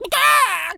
Animal_Impersonations
chicken_cluck_scream_long_05.wav